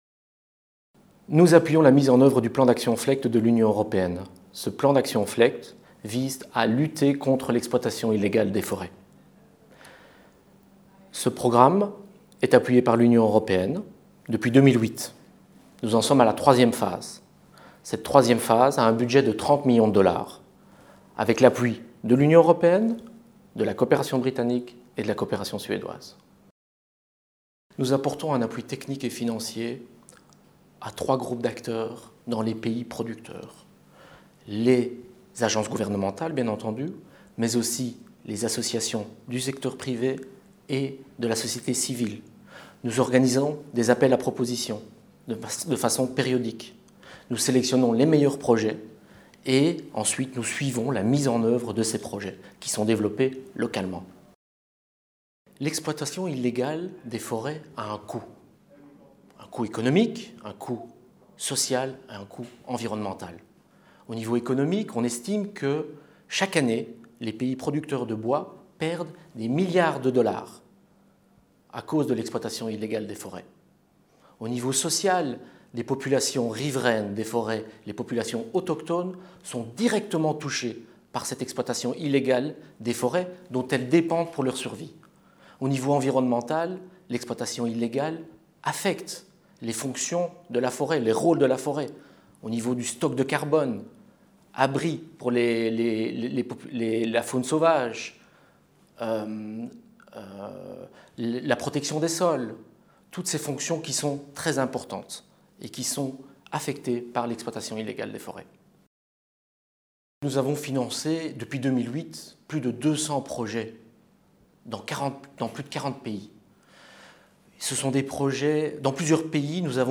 Sujet(s): Biodiversité, Changement climatique, Désertification, Environnement/Ressources naturelles, Interview